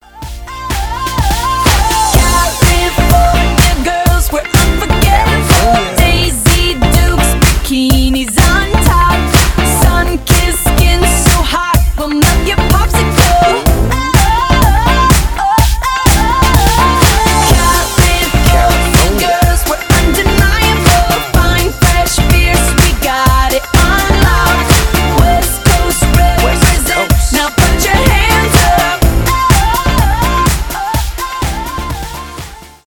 диско , фанк
поп , зажигательные , танцевальные